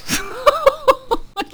cudgel_ack6.wav